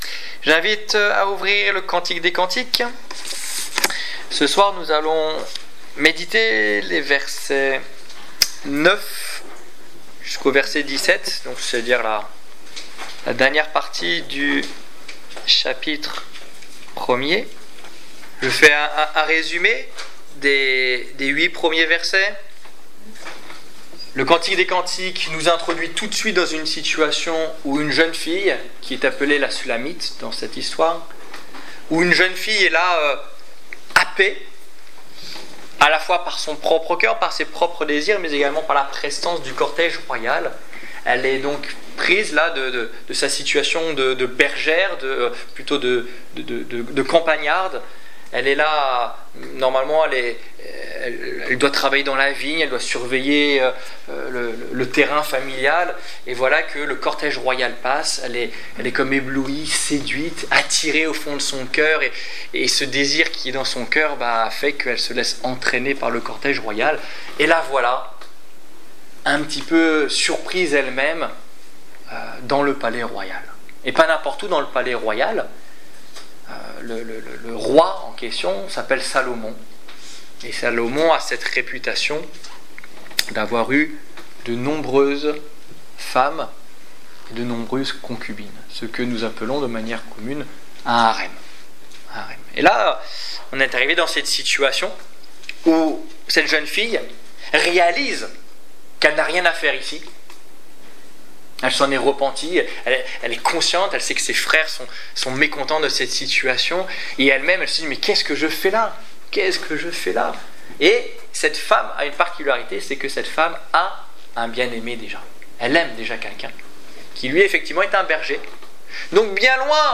Étude biblique du 8 juillet 2015